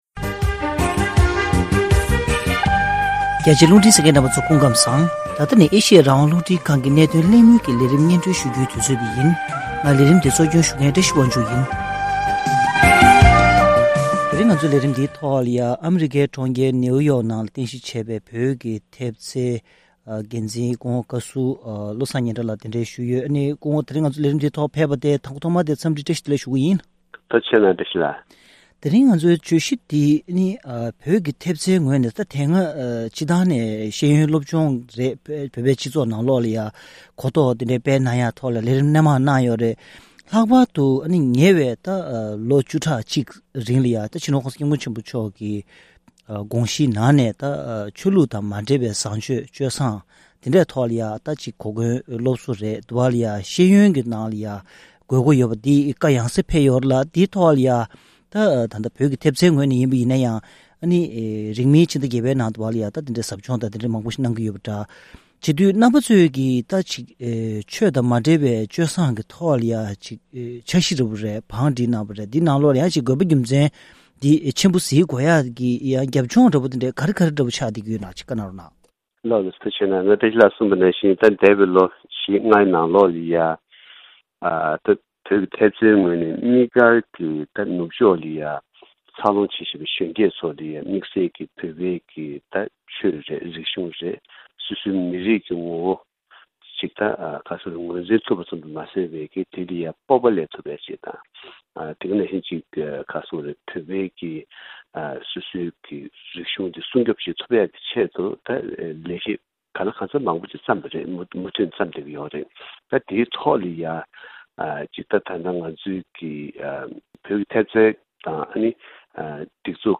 ཐེངས་འདིའི་གནད་དོན་གླེང་མོལ་གྱི་ལས་རིམ་ནང་།